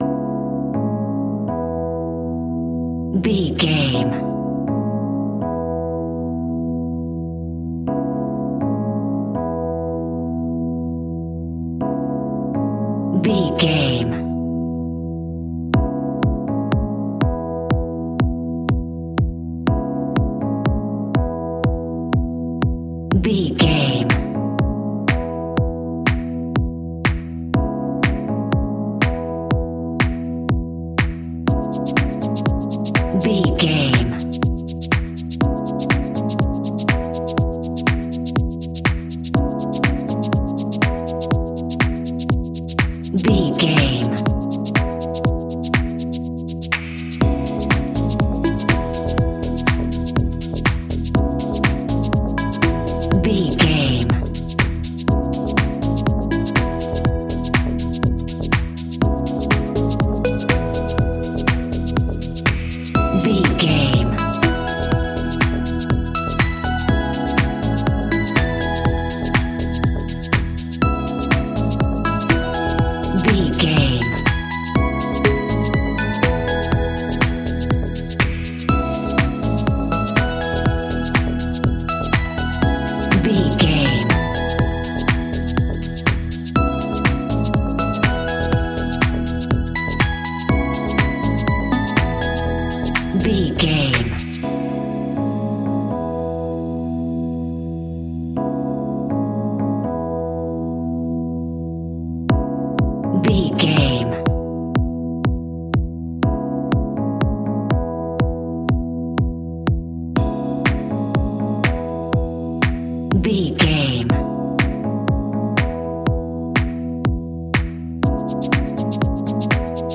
Ionian/Major
groovy
uplifting
futuristic
driving
energetic
repetitive
synthesiser
electric piano
drum machine
electro house
funky house
synth leads
synth bass